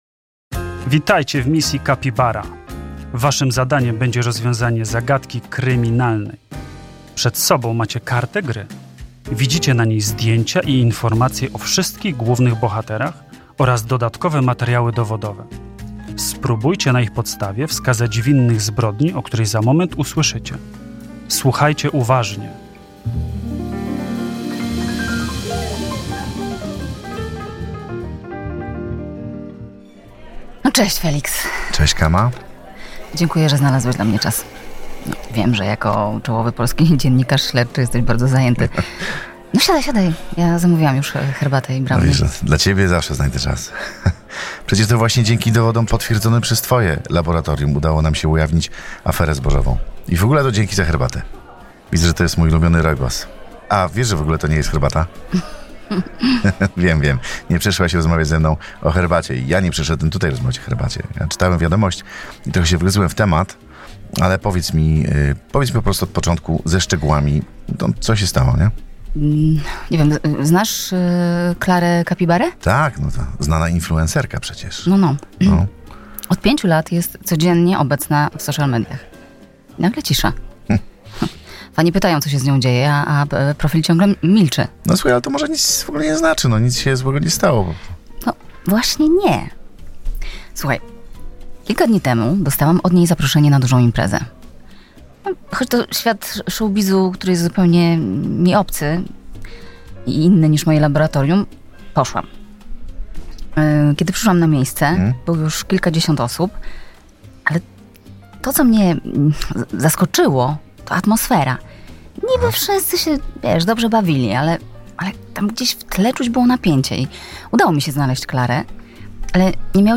Misja Kapibara to gra w formie podcastu (głosu użyczyli: Tamara Arciuch i Bartek Kasprzykowski). Zawiera 20 minutową historię detektywistyczną, w czasie której uczestnicy dostają do zrobienia pięć zadań.